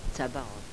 cabâr, pr. zabáor,